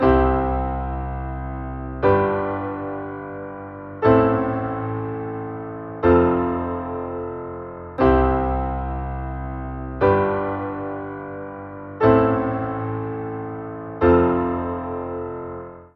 It sounds natural, familiar, and the presence of that vi minor chord gives it an even more melodic quality.
This is the I-V-vi-IV progression in C major, that being Cmaj-Gmaj-Amin-Fmaj:
I-V-vi-IV chord progression